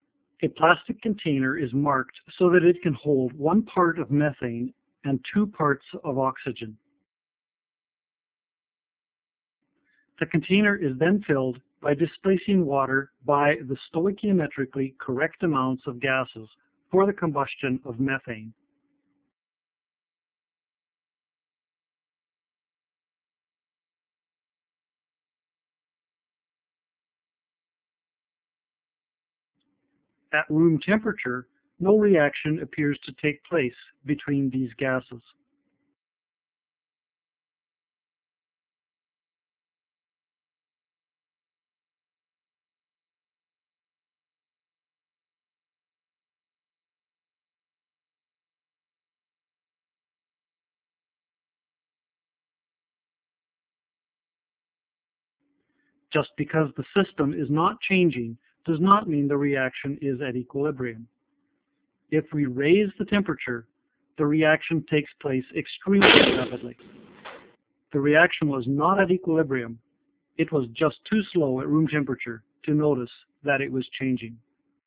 Potential exists to cause serious eye or ear damage.
Explosive combustion of methane.